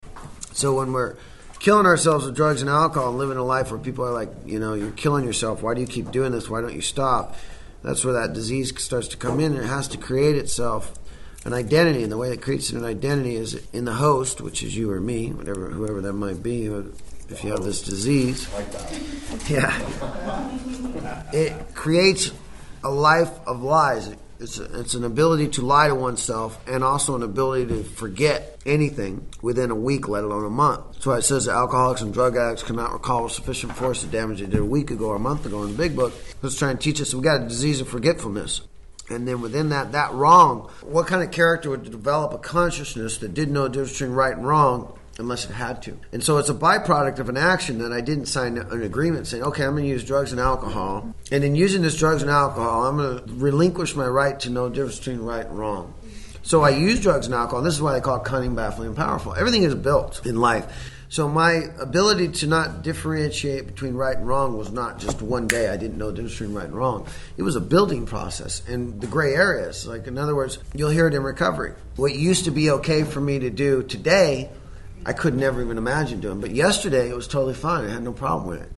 Within the lectures, you will hear people ask questions about why am I where I am, how can I get to a better place and what is blocking me.
A discussion on how addiction clouds judgment and how recovery rebuilds an understanding of right and wrong through spiritual growth and daily practice.